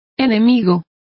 Complete with pronunciation of the translation of foe.